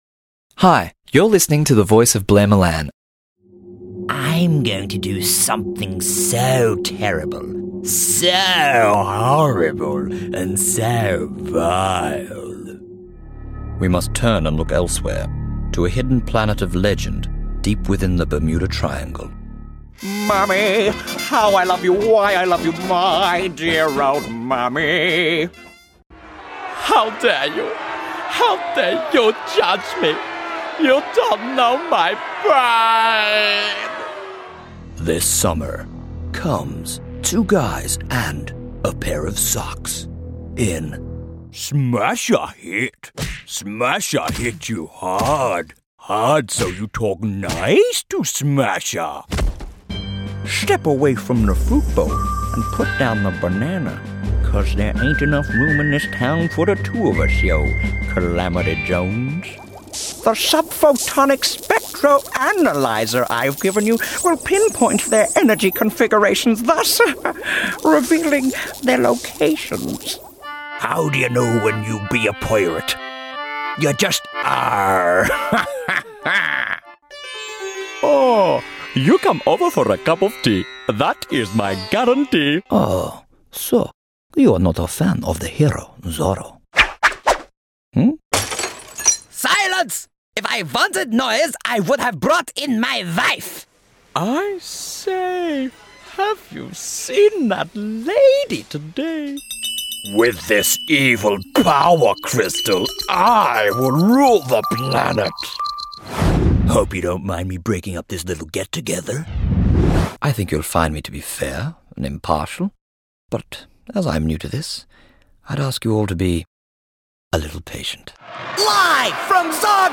Character & Animation